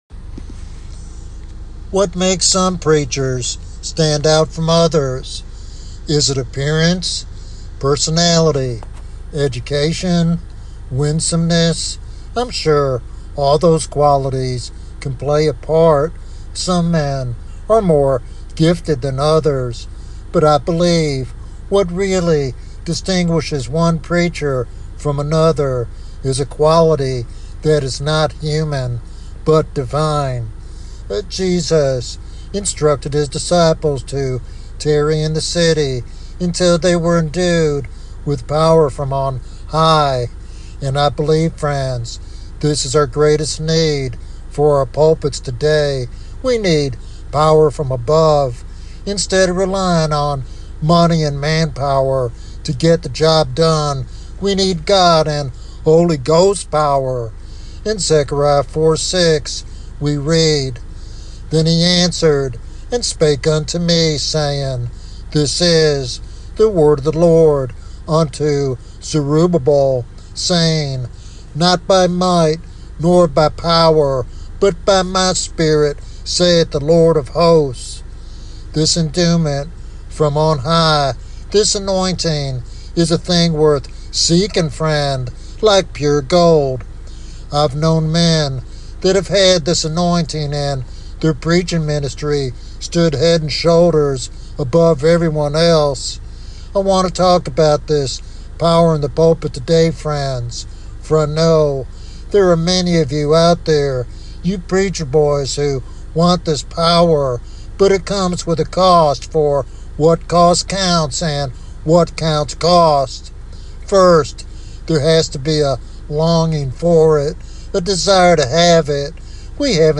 This sermon serves as both an encouragement and a call to revival for the modern church.